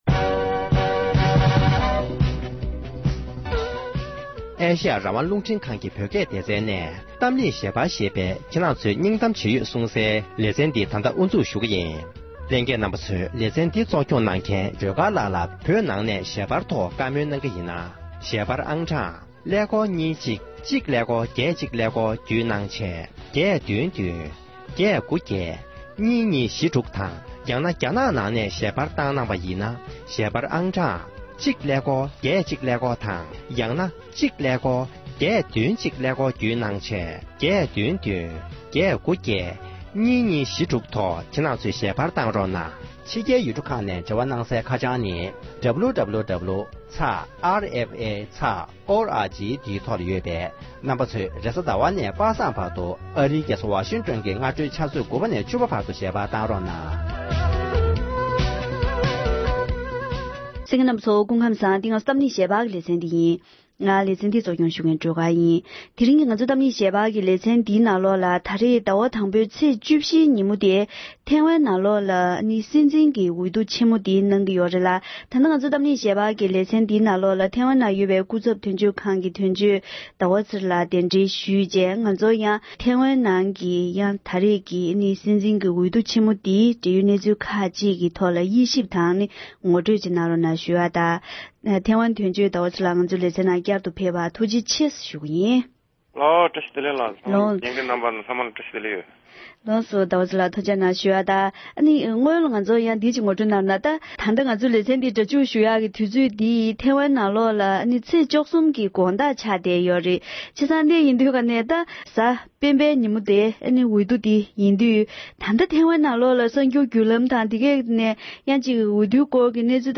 ཐེ་དབན་གྱི་འོས་བསྡུའི་སྐོར་ཐེ་དབན་དོན་གཅོད་སྐུ་ངོ་ཟླ་བ་ཚེ་རིང་ལགས་ཀྱིས་ངོ་སྤྲོད་གནང་བ།